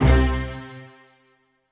STR HIT C2 2.wav